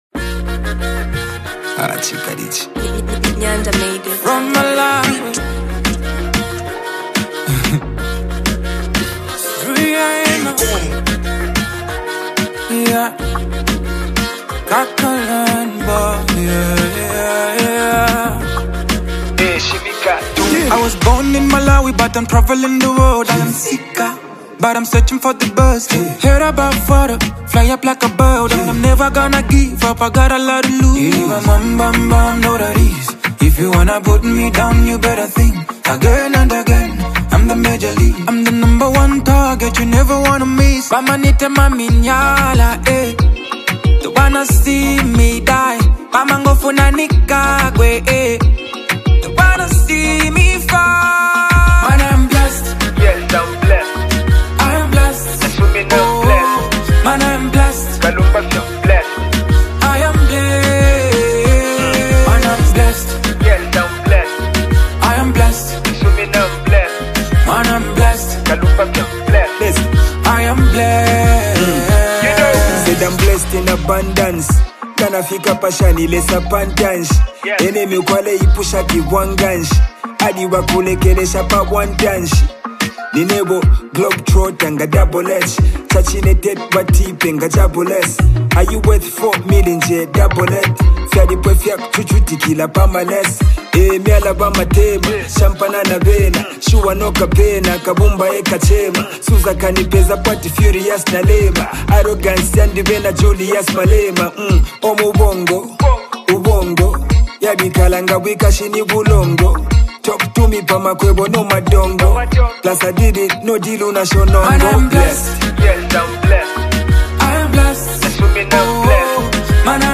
Afro-Dancehall